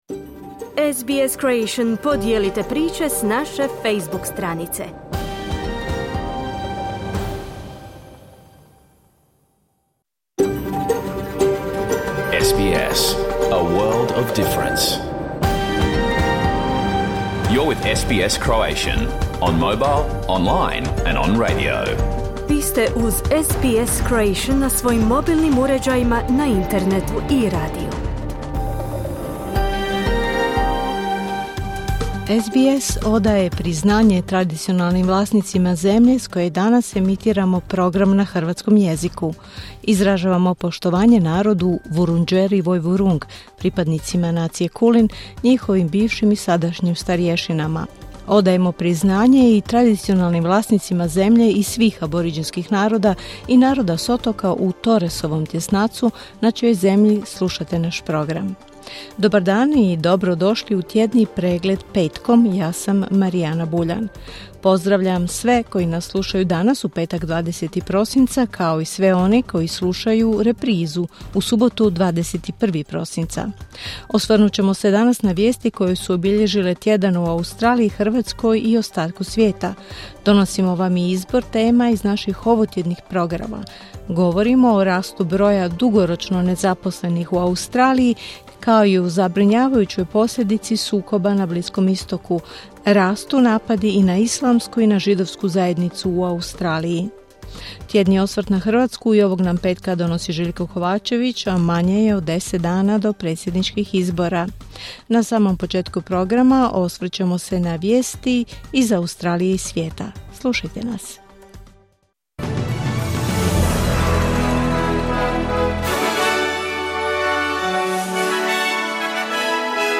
Vijesti i aktualne teme iz Australije, Hrvatske i svijeta. Emitirano na radiju SBS1 u 11 sati, po istočnoaustralskom vremenu.